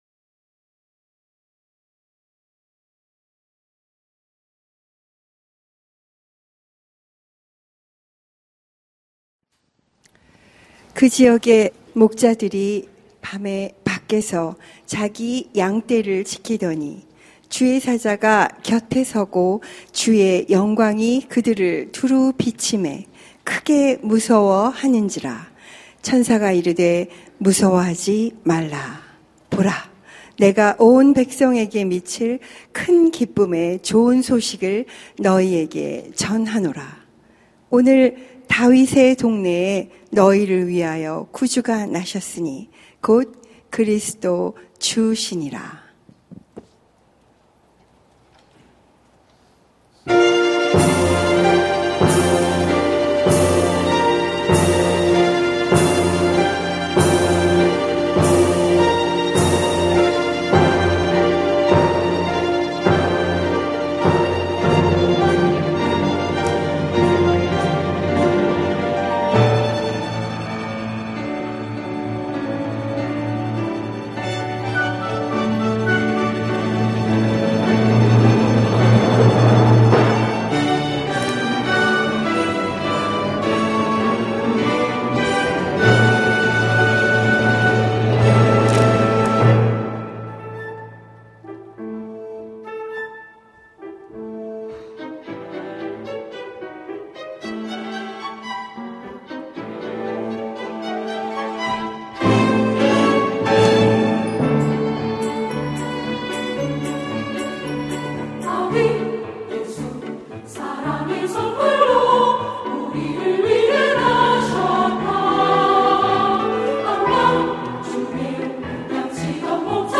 성탄절 칸타타